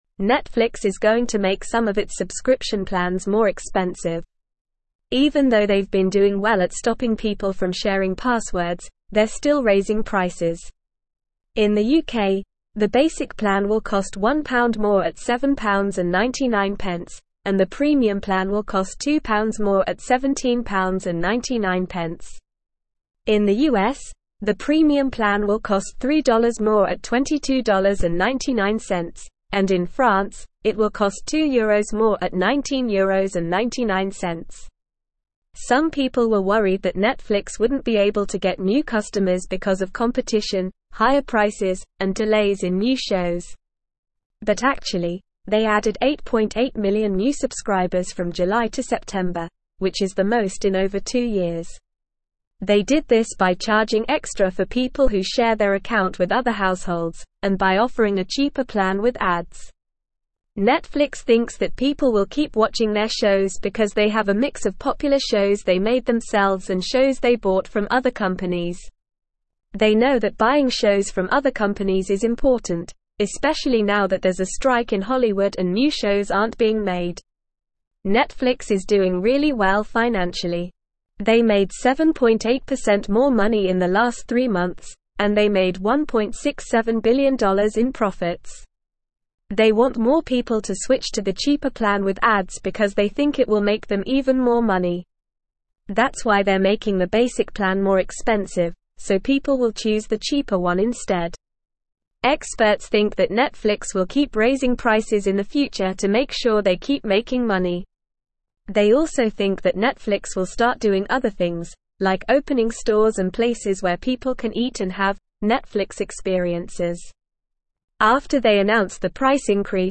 English-Newsroom-Upper-Intermediate-NORMAL-Reading-Netflix-Raises-Subscription-Prices-Despite-Recent-Success.mp3